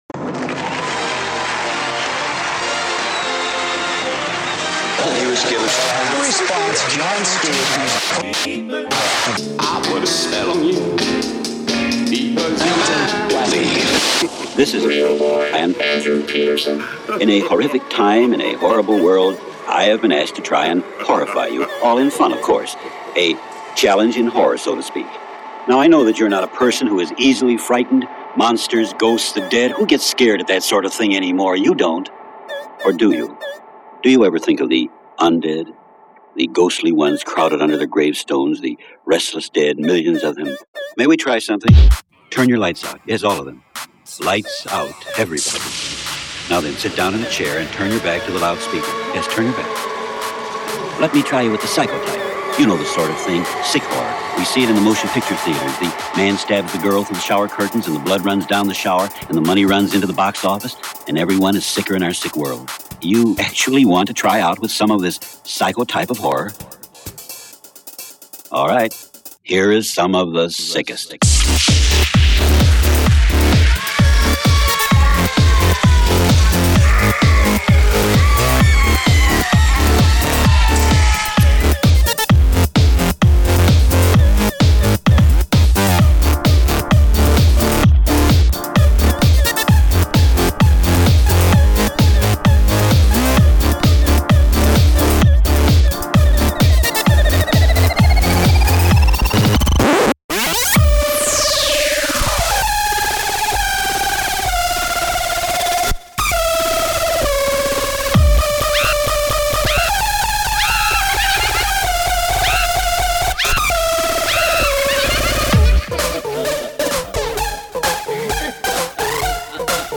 This mix is precisely what awakens my id and drives it to possess me.
I did not do any production on this mix before or after I mixed it.